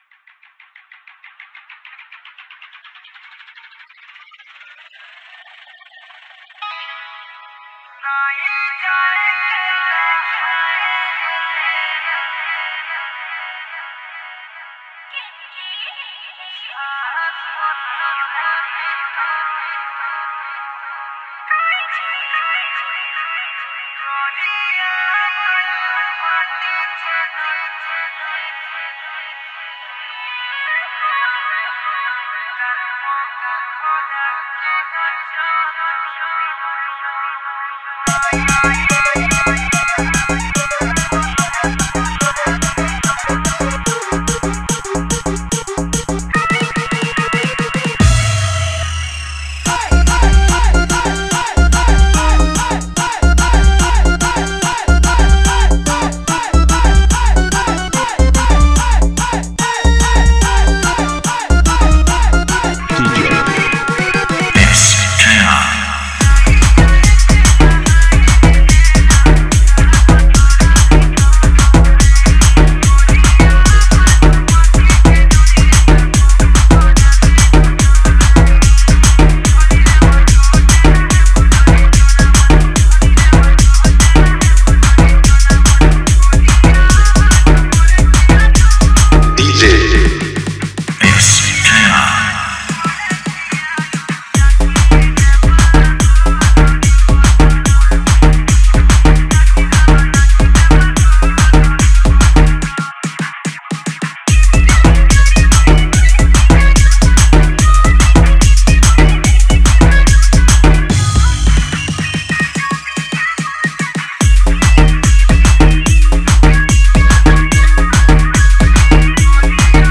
Category: Sambalpuri DJ Song 2022